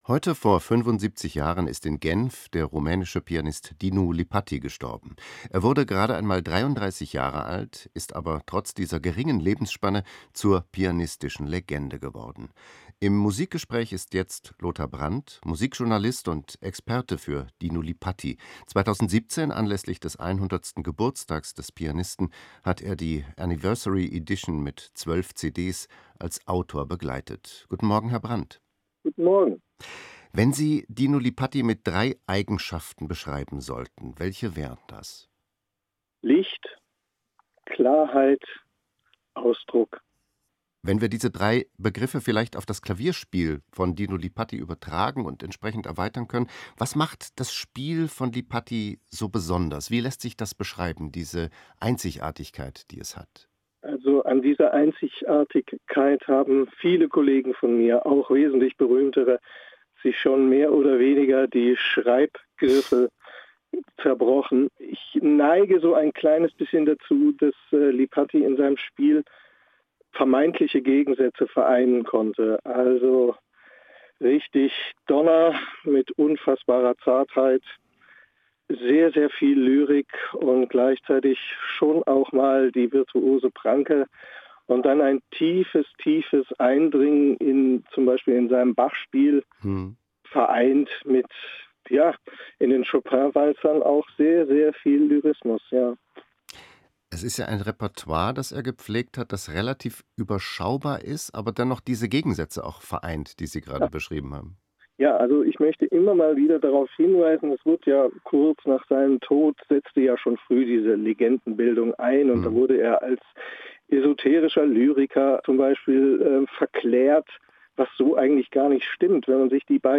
Musikgespräch